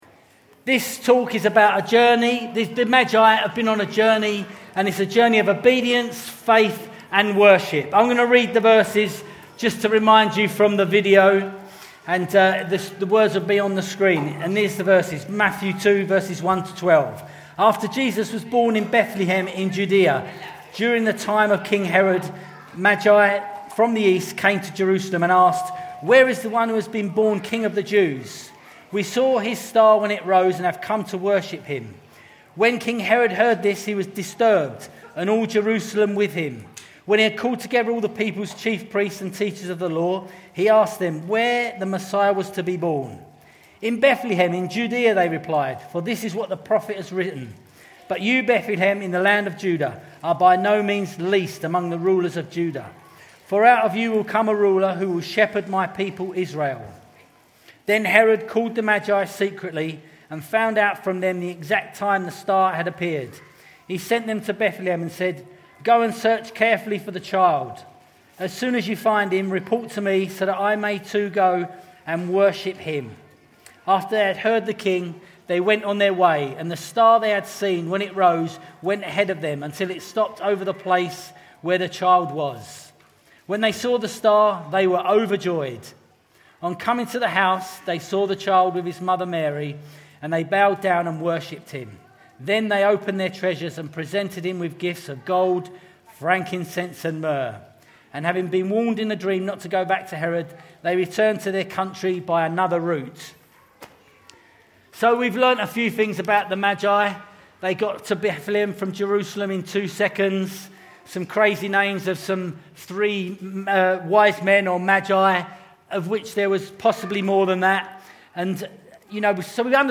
Grace Church Chichester, Bognor Regis and Havant
Series: Other Sermons 2024